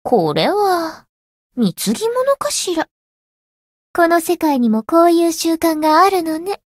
灵魂潮汐-梦咲音月-七夕（送礼语音）.ogg